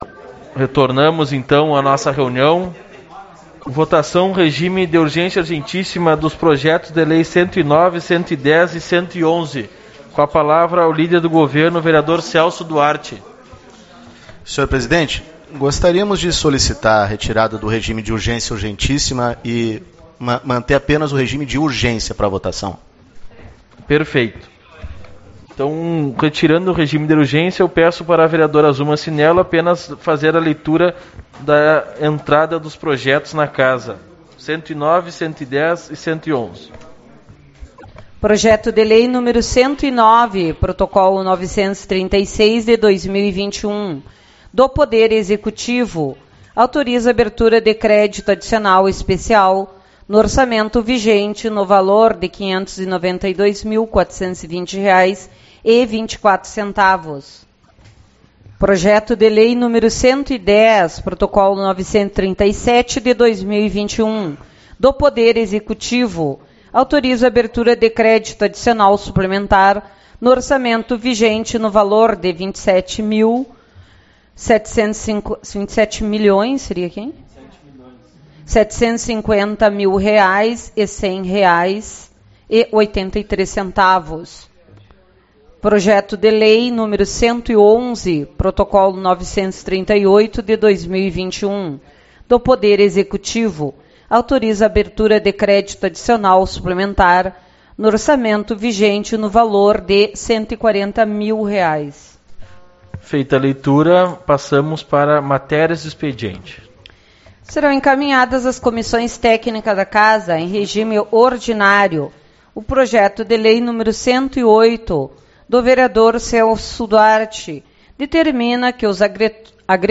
02/09 - Reunião Ordinária / Parte 4